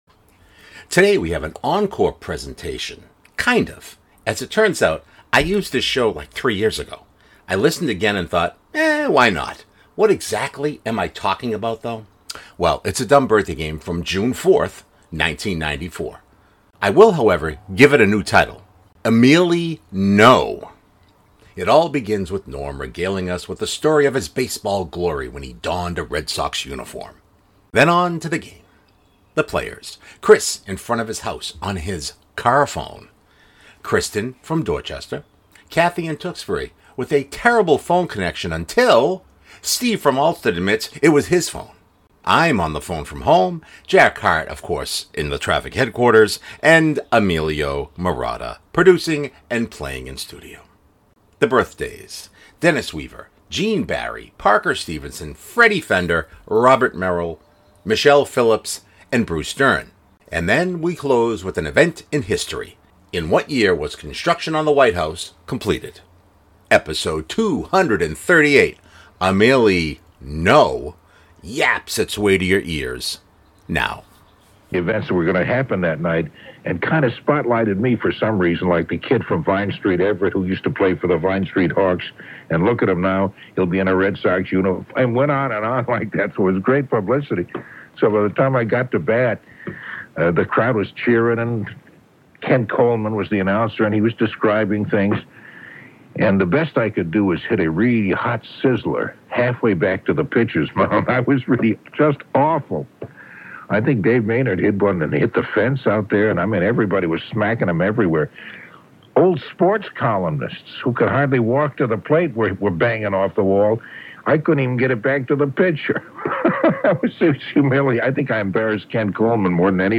Today we have an encore presentation, kind of.